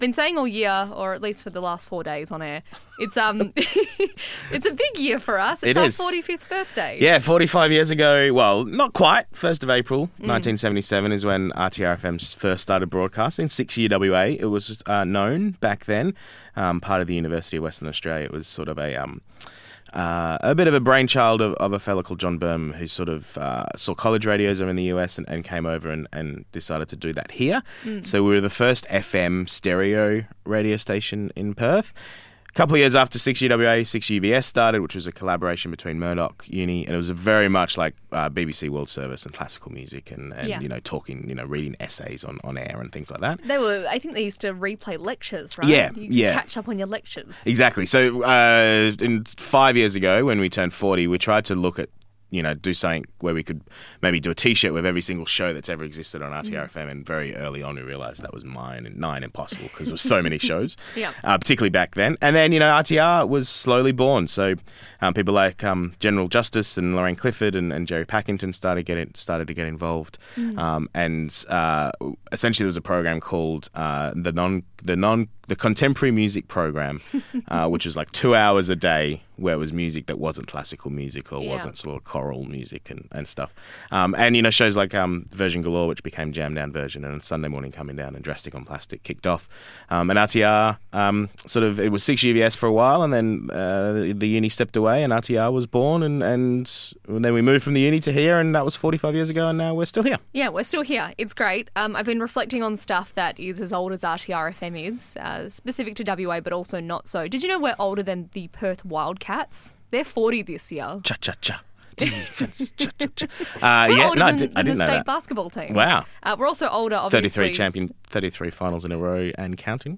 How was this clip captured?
Tickets – “SINCE ’77” Celebrating 45 Years Of RTR FM Breakfast RTR FM Turns Forty-Five